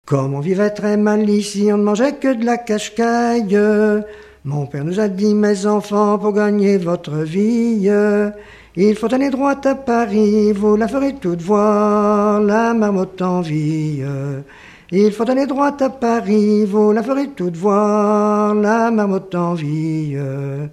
Enfantines diverses
Pièce musicale inédite